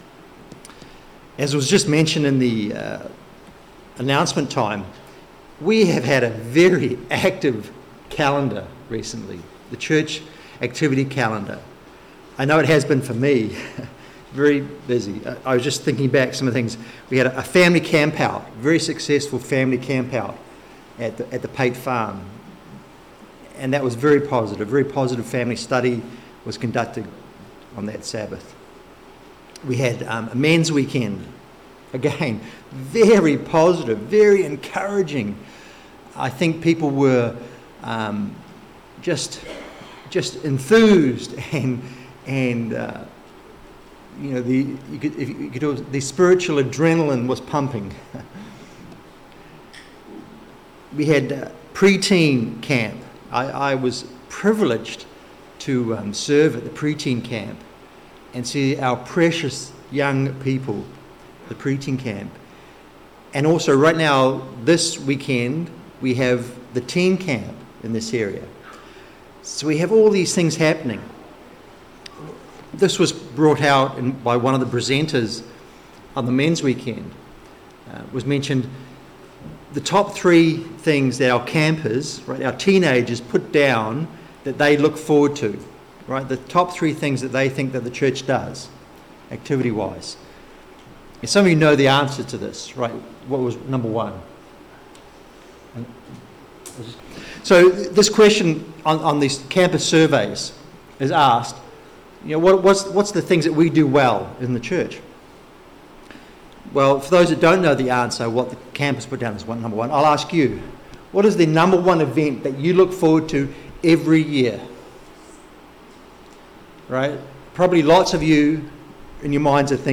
This sermon message builds on that theme from a congregational perspective, building on three points we find in Hebrews 12:1-2. It reflects on the goal, the obstacles and the encouragement to run with endurance, knowing that we are running the race together.